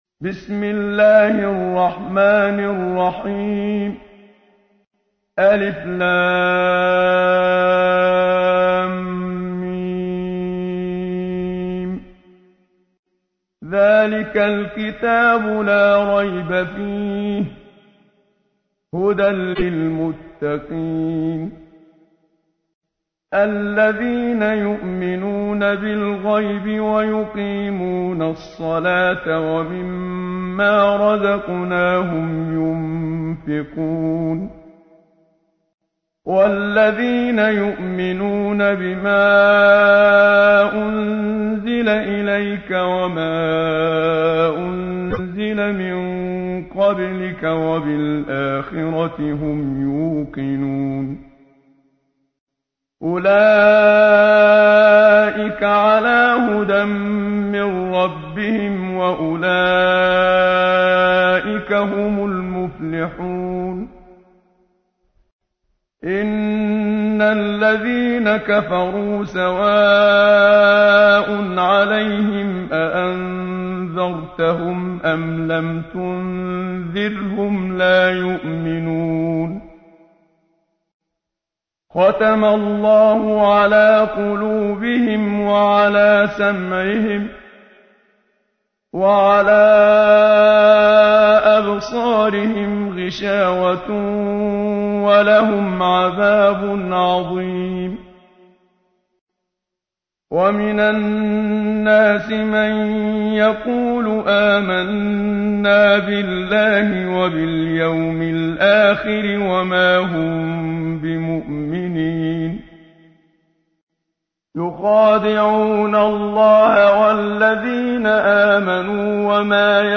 تحميل : 2. سورة البقرة / القارئ محمد صديق المنشاوي / القرآن الكريم / موقع يا حسين